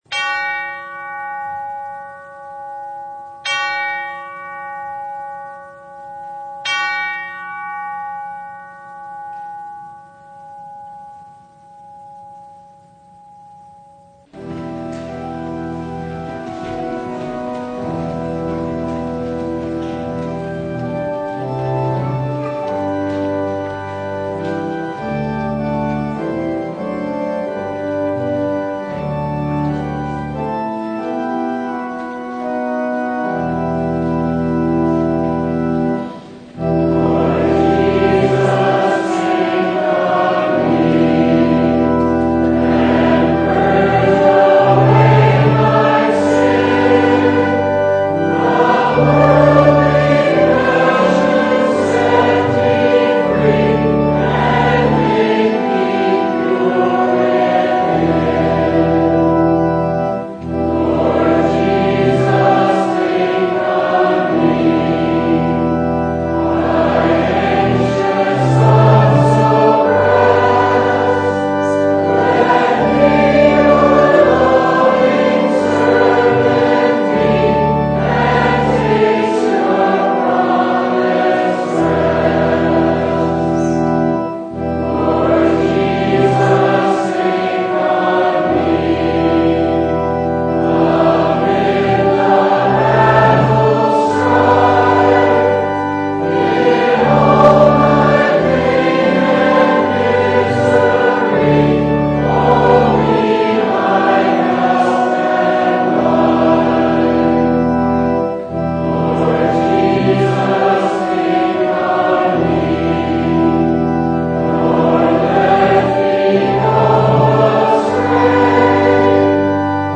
Service Type: Sunday
Download Files Bulletin Topics: Full Service « What is Baptism?